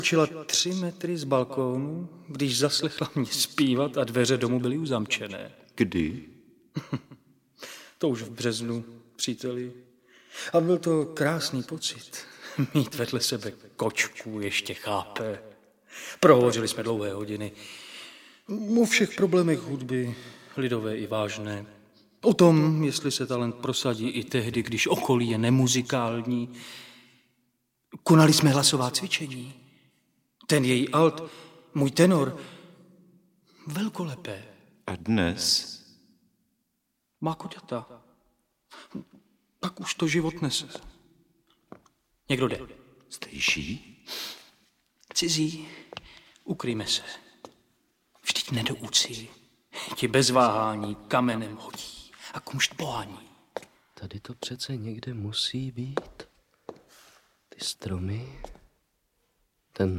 Audiobook
Read: Otakar Brousek